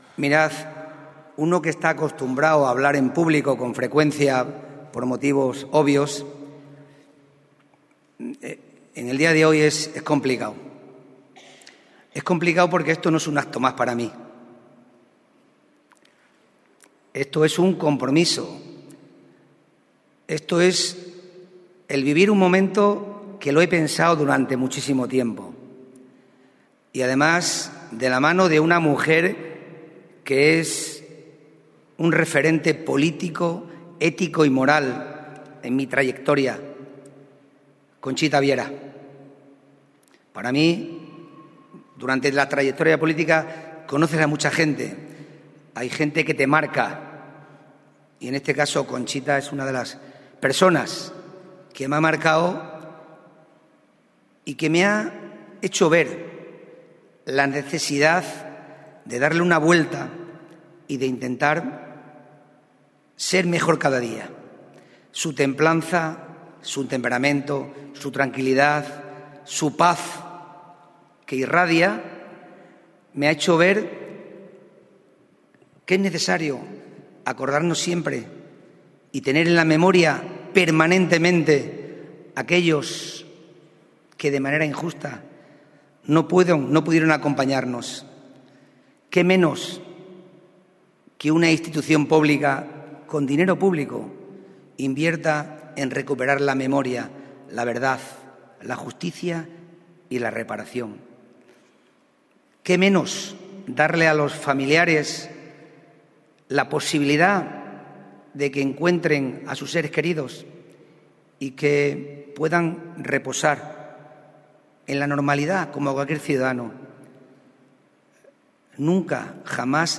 CORTES DE VOZ
Miguel Ángel Morales 1_Presidente de la Diputación de Cáceres